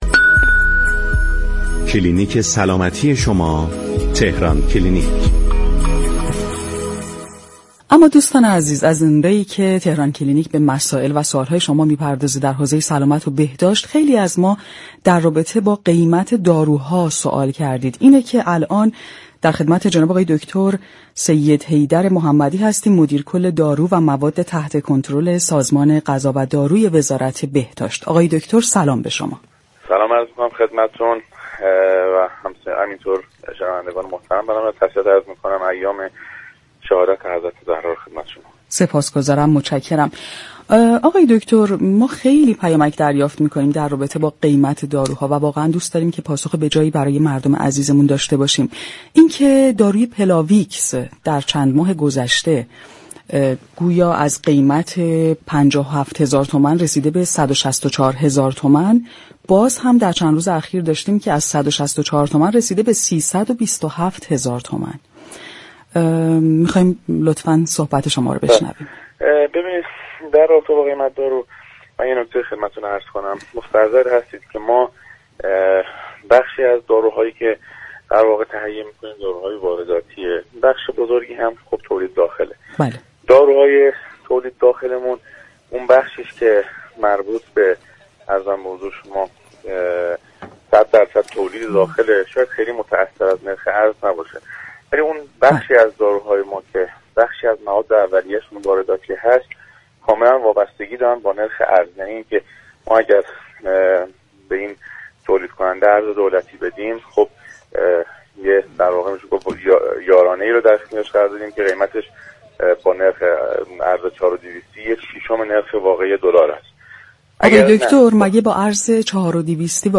مدیر كل دارو و مواد تحت كنترل سازمان غذا و داروی وزارت بهداشت درباره قیمت داروهای تحت لیسانس در كشور گفت: قیمت داروهای تحت لیسانس در داخل ایران 60 تا 80 درصد قیمت دیگر نقاط جهان است.